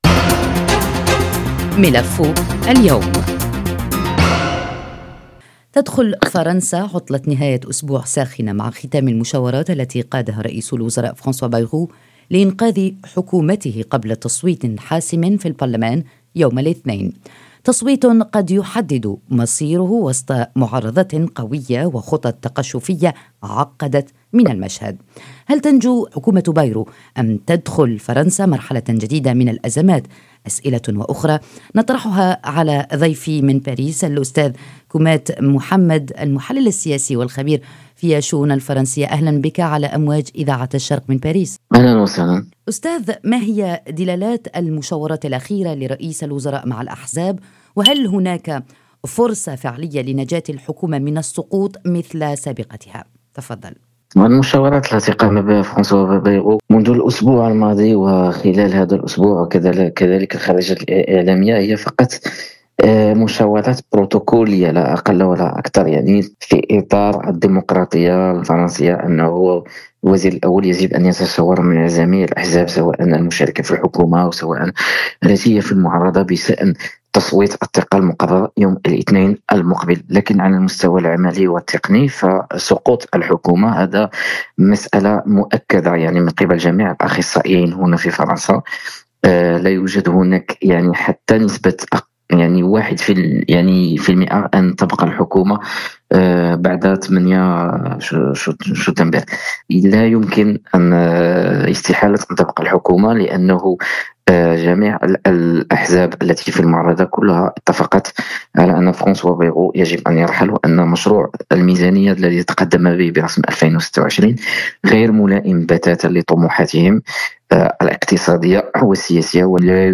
هذا السؤال الذي سيجيب عليه ضيف ملف اليوم.